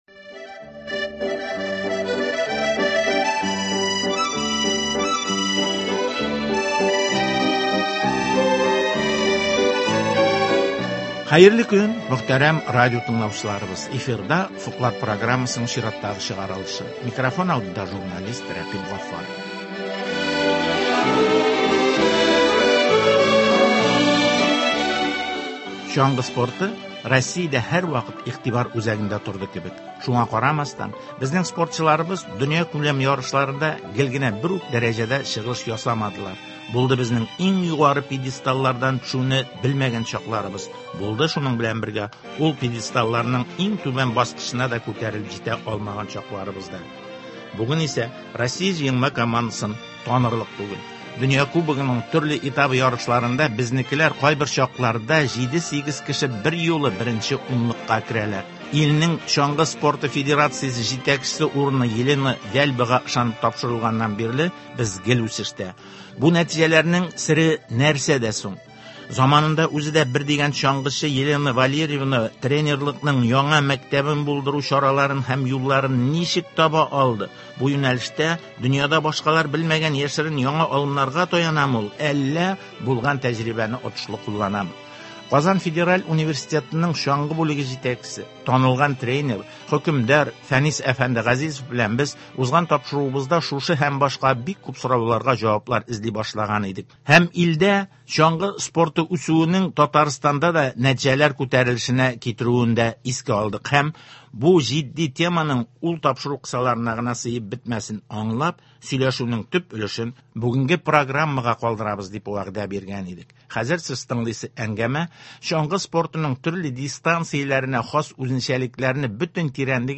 Кырыс пандемия шартларында физкультура һәм спорт белән шөгыльләнүнең мөһимлеге, чаңгы һәм биатлон төрләрен үстерүнең аерым юллары һәм Татарстан спортчыларының халыкара ярышларда катнашуы хакында әңгәмә. Тапшыруда шулай ук балалар спортына багышланган фикерләр дә әйтелә.